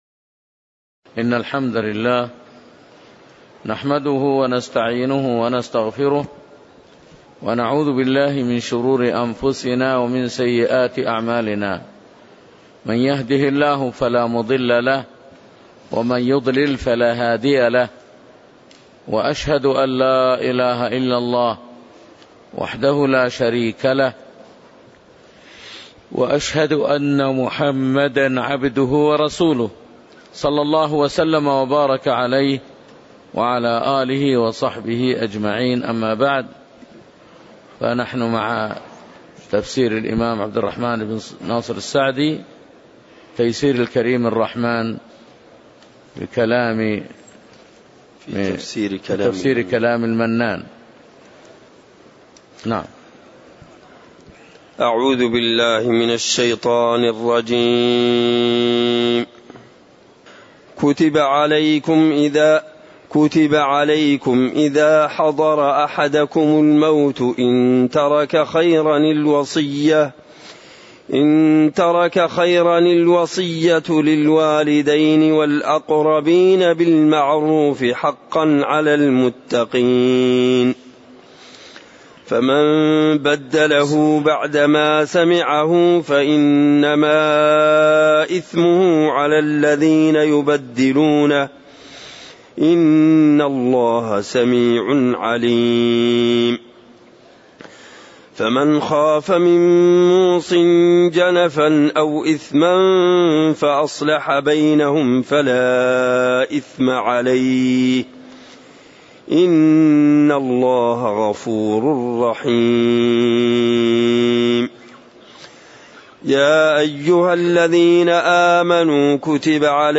تاريخ النشر ٢٤ شعبان ١٤٣٨ هـ المكان: المسجد النبوي الشيخ